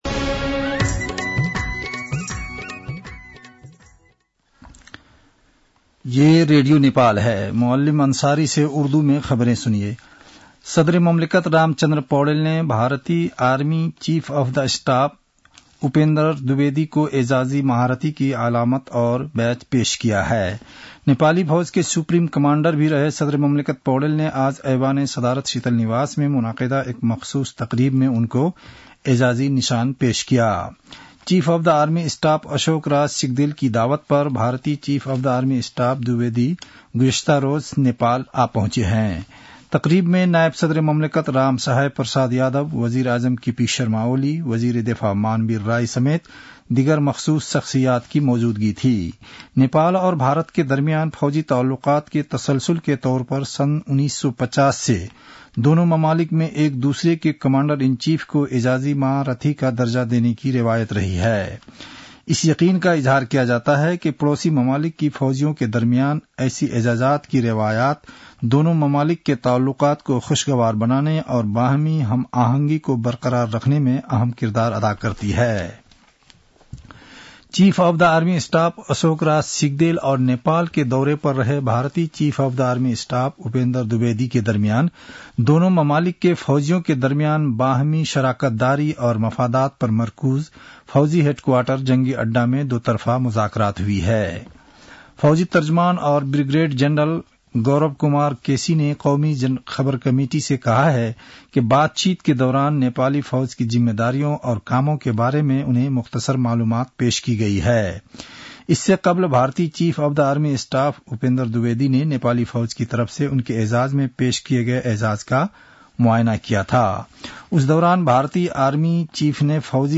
उर्दु भाषामा समाचार : ७ मंसिर , २०८१
Urdu-news-8-6.mp3